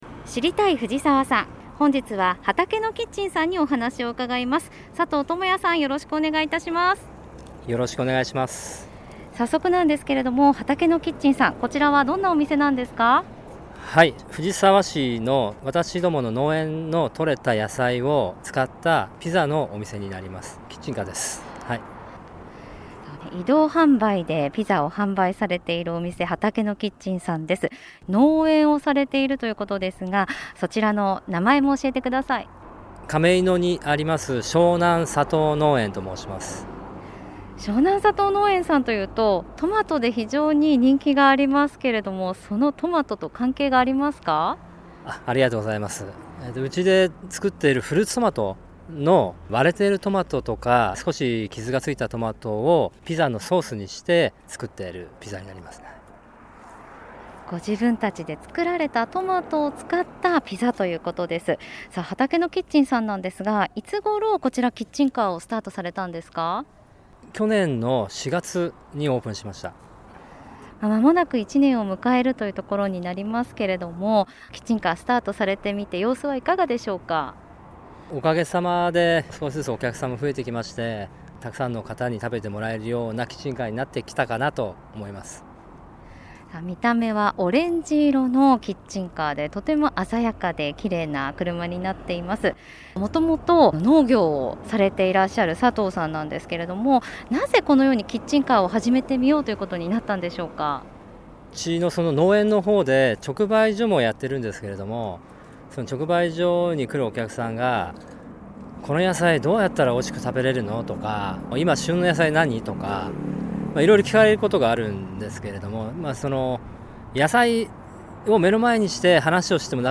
令和3年度に市の広報番組ハミングふじさわで放送された「知りたい！藤沢産」のアーカイブを音声にてご紹介いたします。